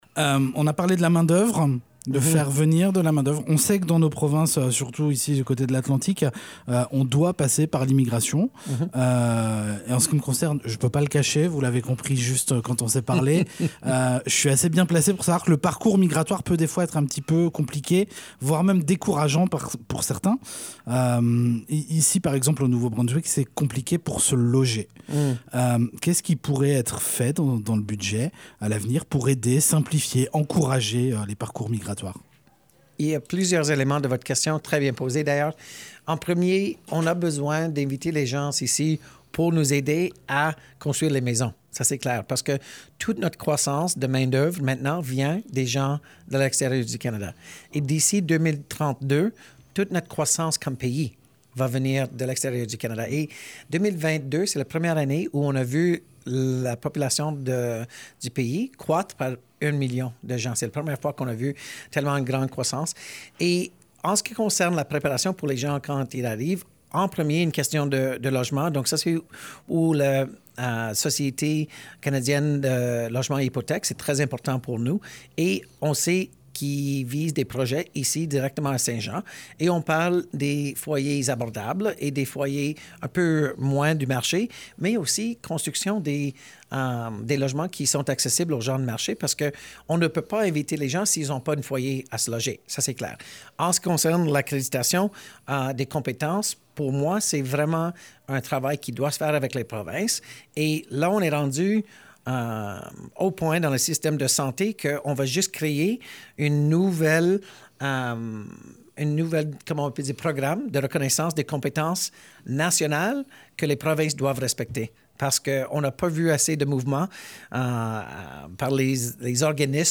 Entre 2 rencontres, le ministre a pris le temps de venir s'exprimer sur les ondes de C105 à Saint-Jean. Dans cette seconde partie d'entrevue, on aborde les sujets de l'immigration, de la main-d'œuvre nécessaire pour l'avenir du Canada, mais aussi de l'avenir du programme de l'Initiative journalisme local pour nos radios communautaires.